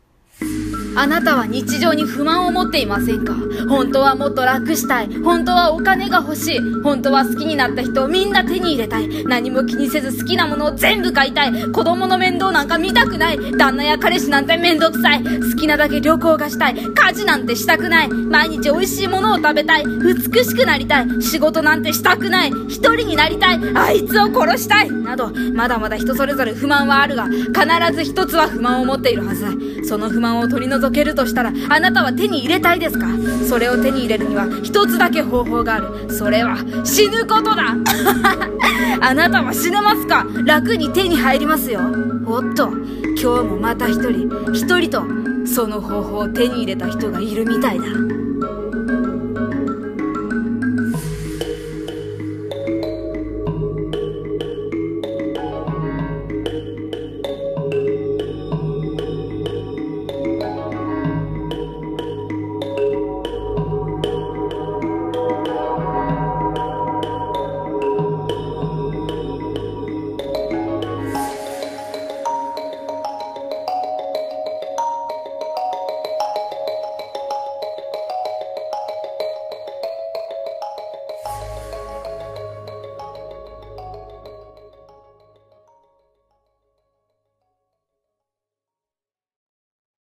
〜不 満〜 【ホラー朗読用台本】